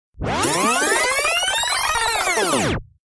Метки: перемотка назад, перемотка,